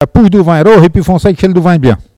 Langue Maraîchin
Patois - archives
Catégorie Locution